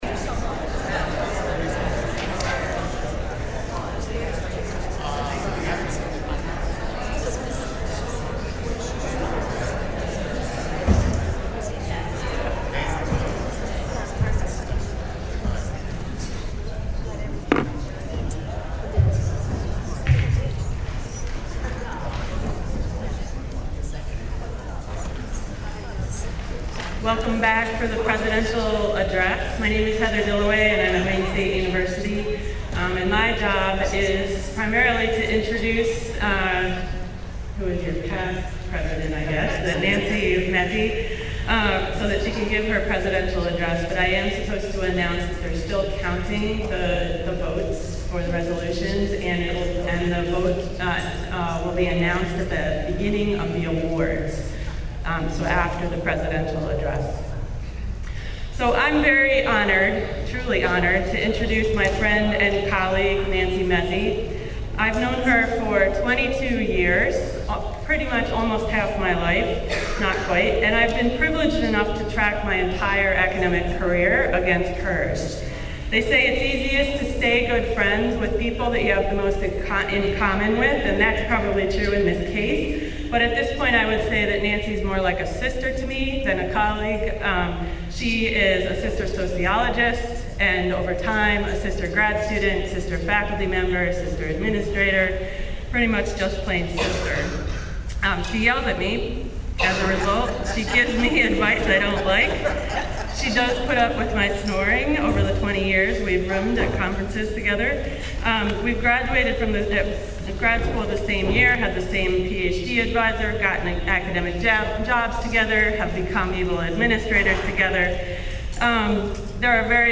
2019 Meeting Highlights
2019 Presidential Address Recording.MP3